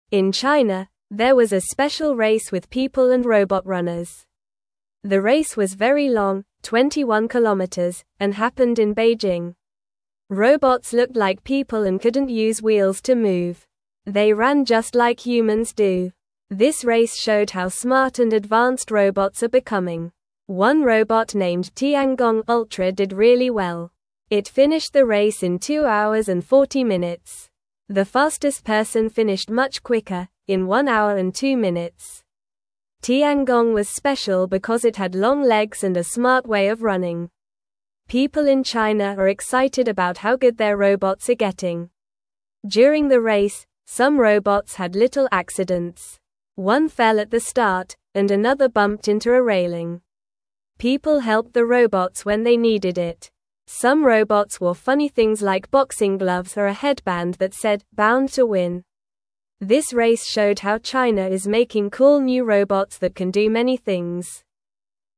Normal
English-Newsroom-Beginner-NORMAL-Reading-Robots-and-People-Race-Together-in-China.mp3